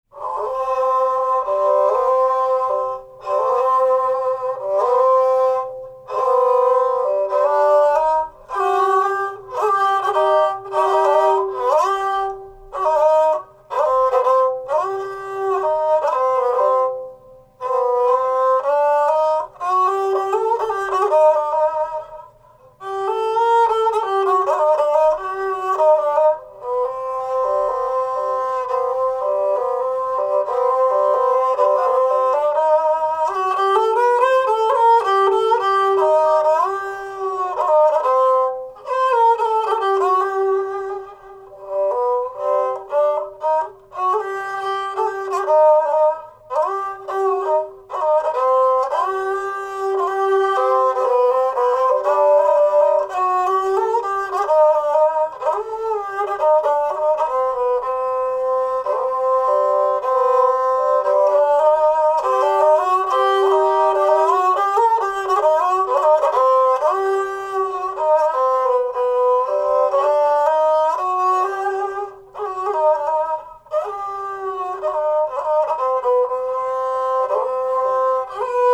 Genre: Turkish & Ottoman Classical.